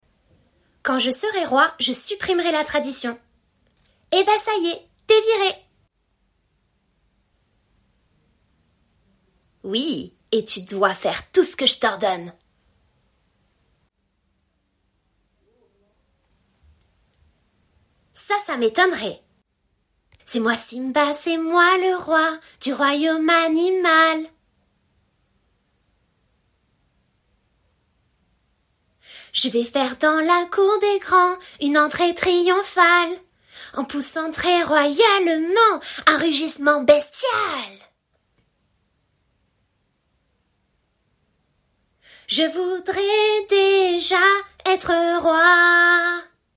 - Soprano